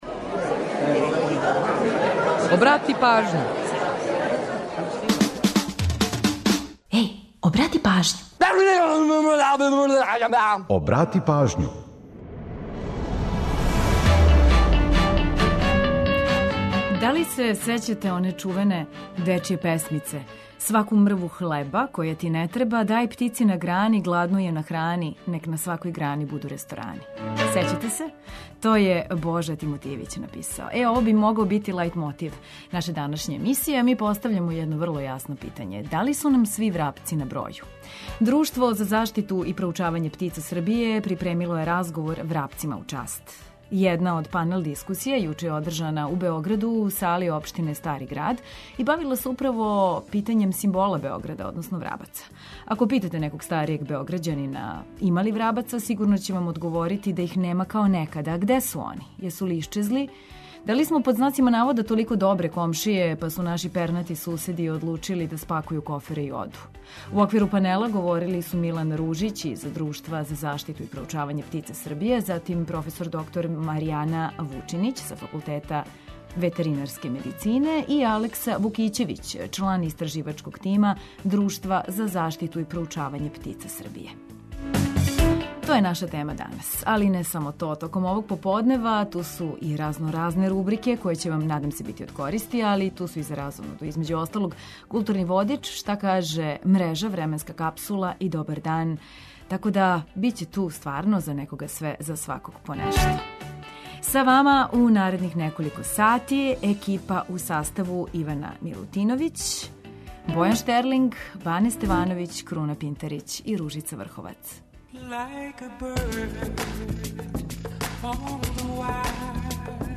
Једна од панел дискусија, јуче одржана у сали општине Стари град бавила се управо питањем врабаца.